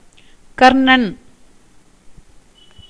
pronunciation) (c. 1933 – 13 December 2012) was an Indian cinematographer and director who mainly worked in Tamil cinema.[1] Starting his career as an assistant to V. Ramamurthi, Karnan gradually rose to a full-fledged cinematographer,[2][3] and made a few films based on curry western featuring Jaishankar in the lead.[4] In an interview to Behindwoods, cinematographer Ravi K. Chandran mentioned Karnan as an inspiration and noted the way he made films.